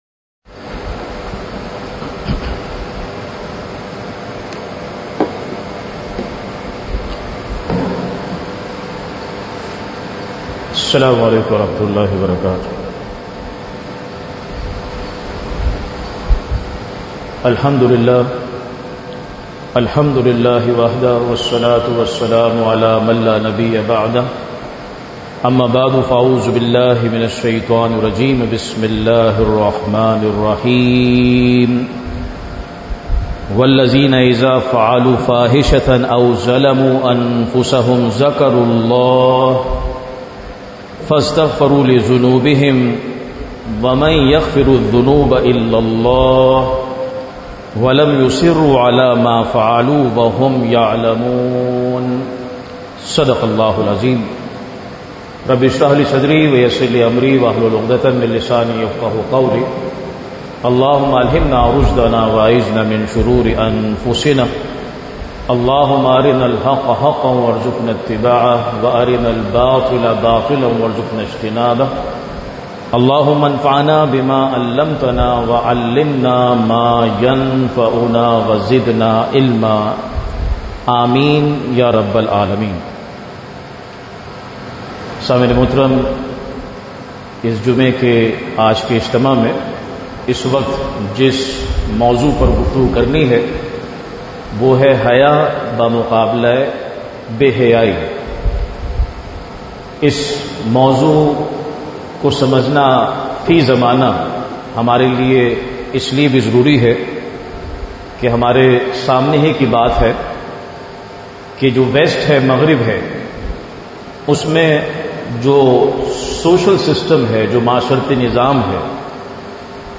Khutbat-e-Jummah (Friday Sermons)
@ Jame Masjid Taibah, Zaman Town Korangi. 2024-08-30 Haya Ba-Muqabla Be-Hayai , حیا بمقابلہ بے حیائی۔